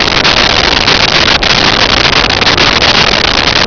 Sfx Surface Stone Loop
sfx_surface_stone_loop.wav